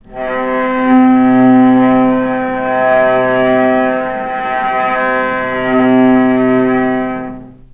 Strings / cello / sul-ponticello
vc_sp-C3-mf.AIF